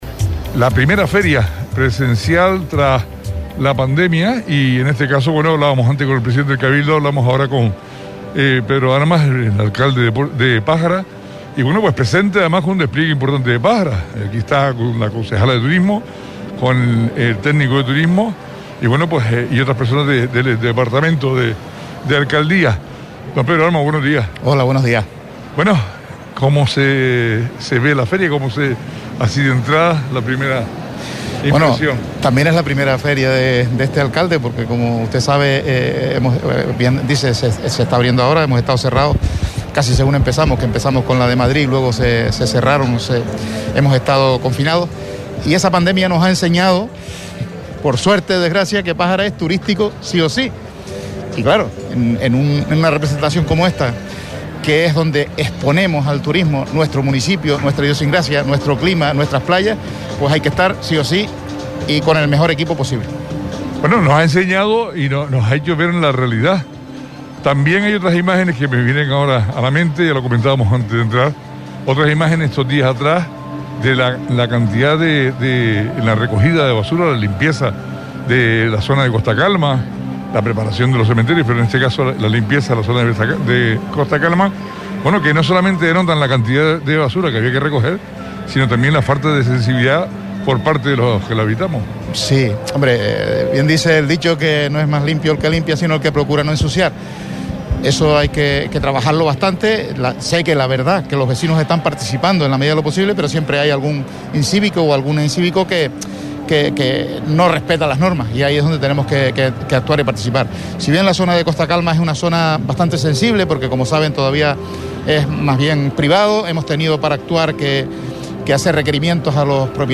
Entrevistas
La emisora de Radio Sintonía se encuentra en Londres para retransmitir en directo todas las novedades que van surgiendo con respecto a Fuerteventura en World Travel Market.